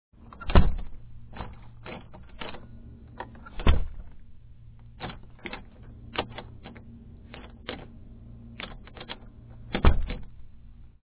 door4.mp3